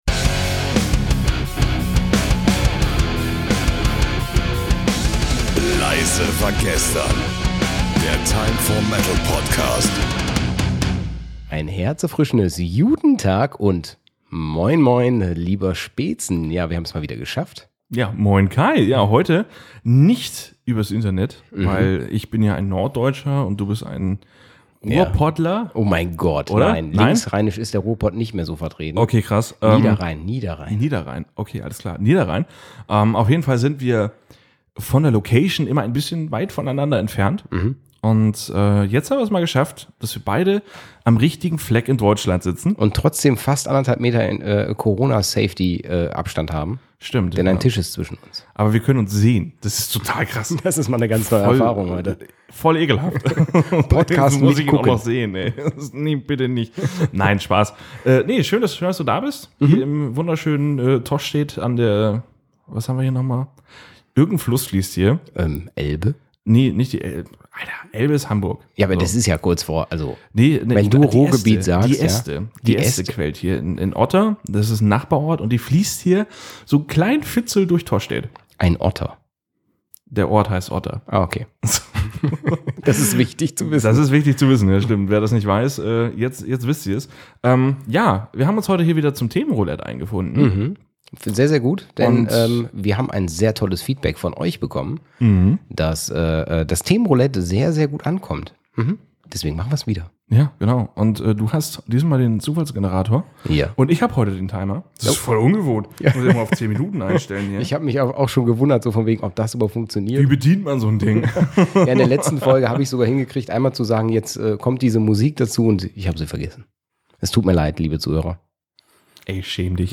Nächste Folge: 10.11.2020 Themenroulette - Die Spielregeln Pro Folge werden per Zufallsgenerator drei Themen gewählt, welche dann von den beiden Moderatoren innerhalb von exakt zehn Minuten behandelt werden.